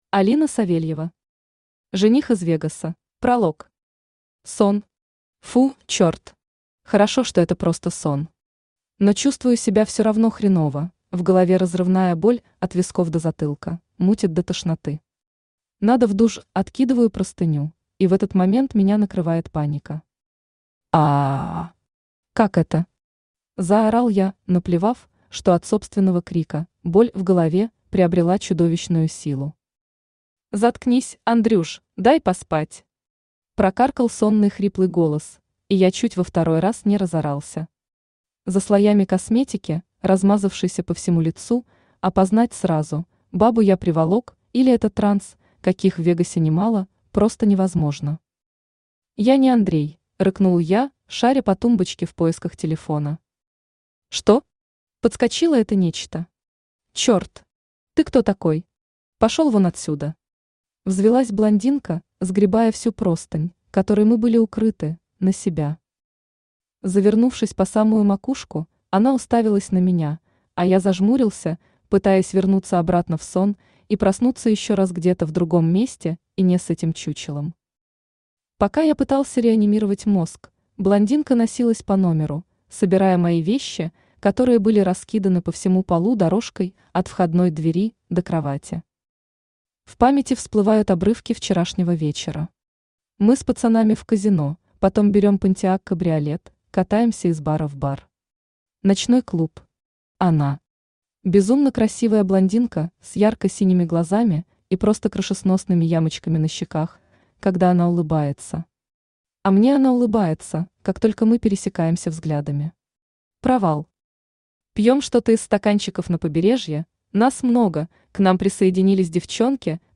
Аудиокнига Жених из Вегаса | Библиотека аудиокниг
Aудиокнига Жених из Вегаса Автор Алина Савельева Читает аудиокнигу Авточтец ЛитРес.